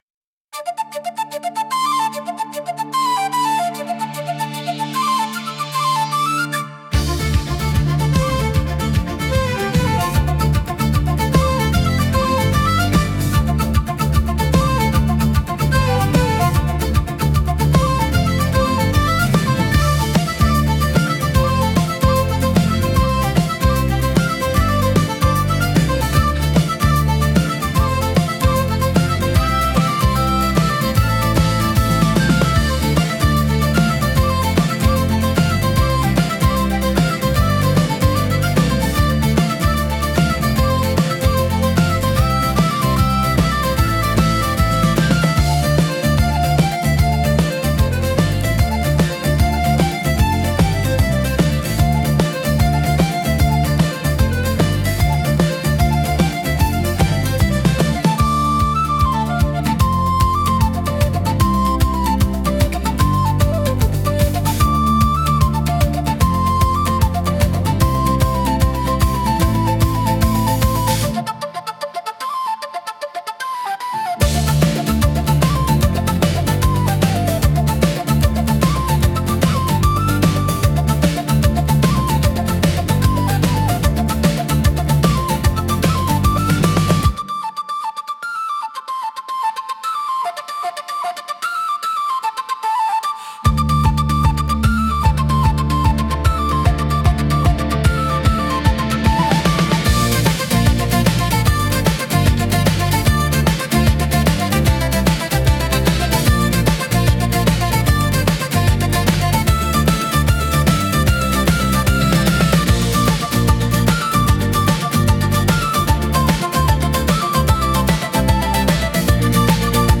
聴く人に親しみやすさと爽やかな感動を届ける民族的で情緒豊かなジャンルです。